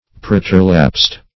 Search Result for " preterlapsed" : The Collaborative International Dictionary of English v.0.48: Preterlapsed \Pre`ter*lapsed"\, a. [L. praeterlapsus, p. p. of praeterlabi to glide by.